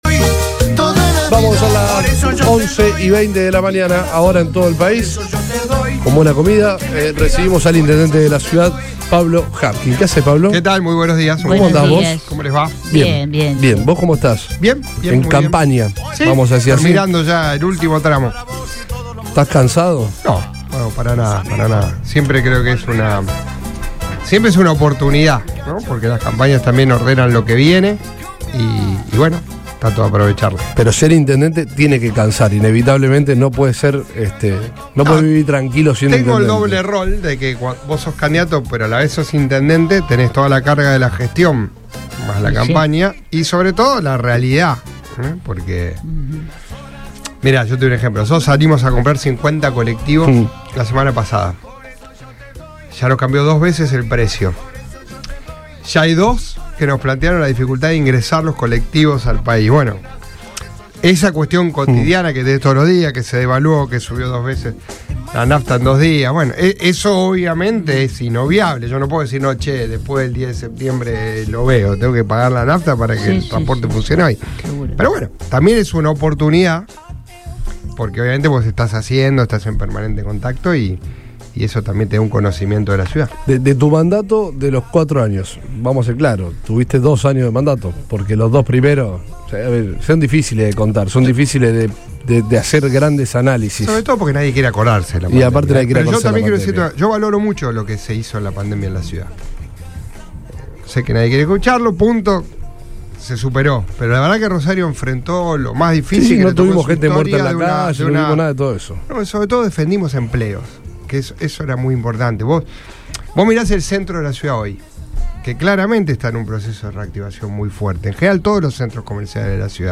El actual intendente de la ciudad y candidato a renovar el cargo pasó por los estudios de Radio Boing, donde dialogó con el equipo de Todo Pasa. Allí, Pablo Javkin aseguró que le tocó gobernar bajo un contexto difícil por la pandemia y ennumeró las gestiones positivas que se hicieron a lo largo de su mandato como máximo funcionario de Rosario.